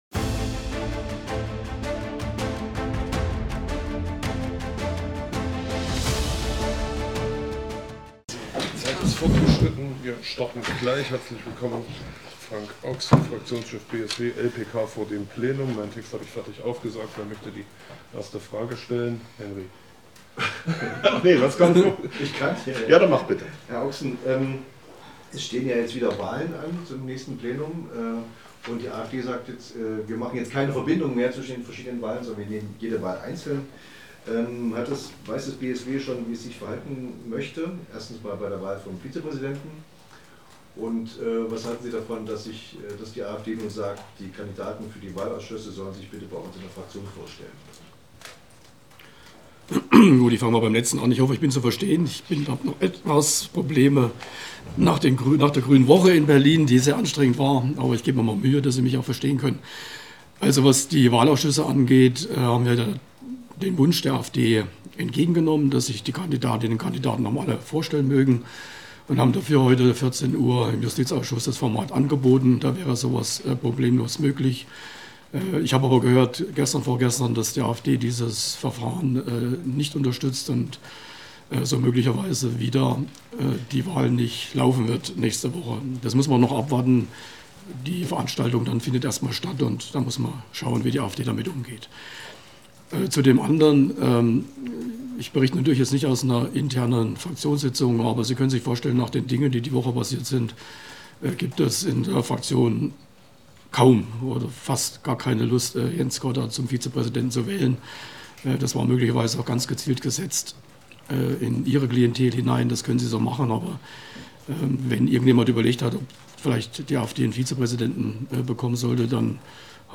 Fazit der letzten Plenarsitzung: Matthias Hey (SPD) im Interview